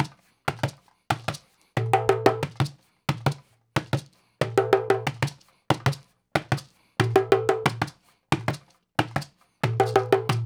92-PERC1.wav